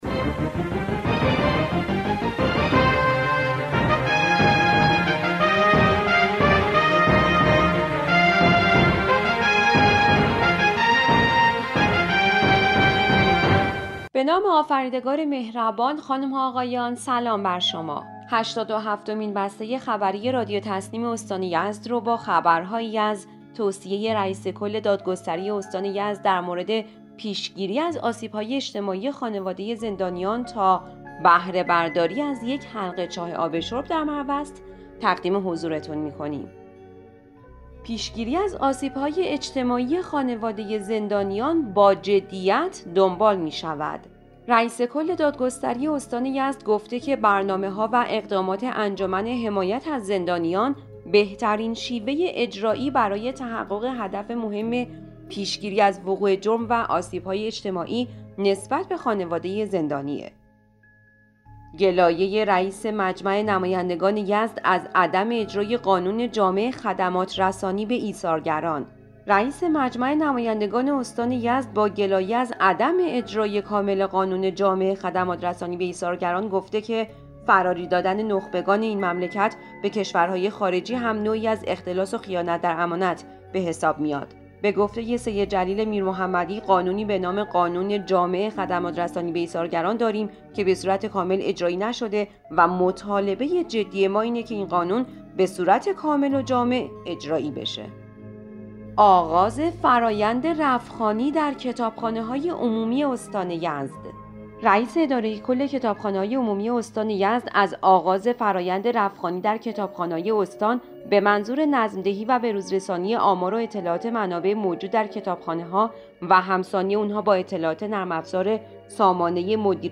به گزارش خبرگزاری تسنیم از یزد, هشتاد و هفتمین بسته خبری رادیو تسنیم استان یزد با خبرهایی از توصیه رئیس کل دادگستری استان در مورد پیشگیری از آسیب‌های اجتماعی خانواده زندانیان, گلایه رئیس مجمع نمایندگان یزد از عدم اجرای کامل قانون جامع خدمات‌رسانی به ایثارگران, آغاز فرآیند رف‌خوانی در کتابخانه‌های عمومی استان, اعلام وضعیت درآمدی استان یزد و بهره‌برداری از یک حلقه چاه آب شرب در مروست منتشر شد.